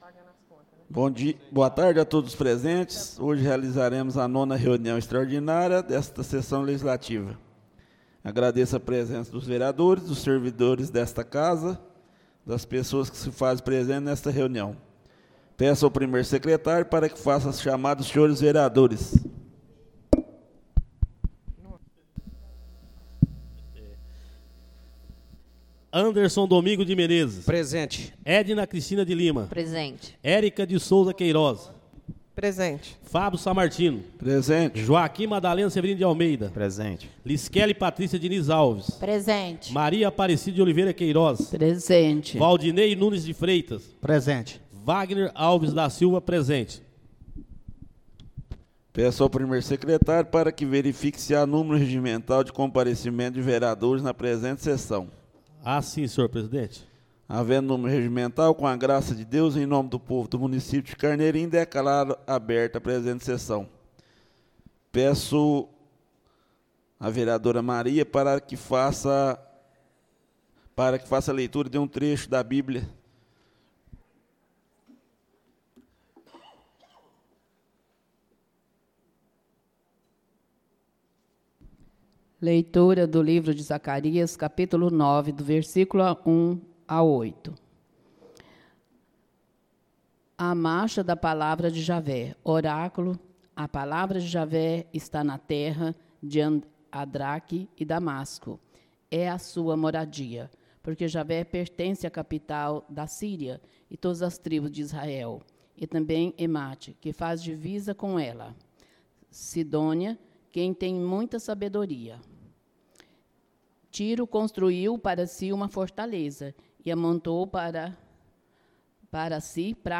Áudio da 09.ª reunião extraordinária de 2025, realizada no dia 21 de julho de 2025, na sala de sessões da Câmara Municipal de Carneirinho, Estado de Minas Gerais.